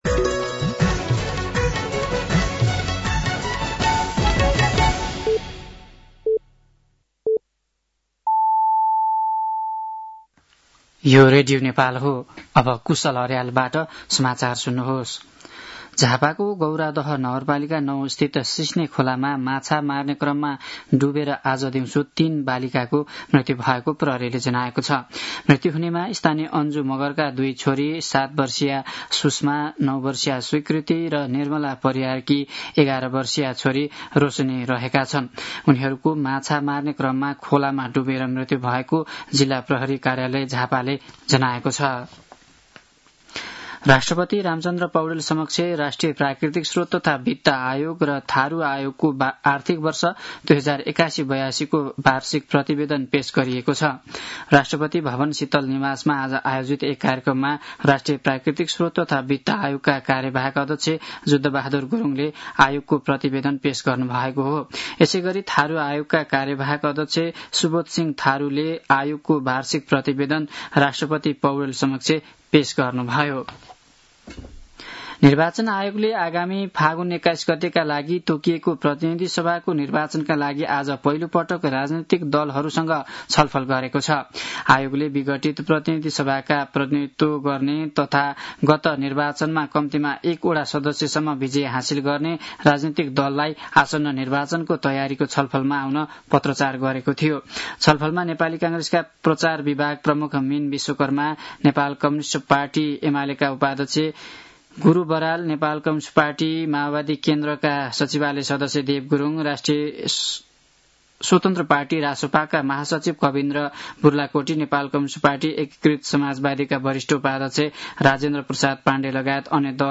साँझ ५ बजेको नेपाली समाचार : ३० असोज , २०८२
5.-pm-nepali-news-1-3.mp3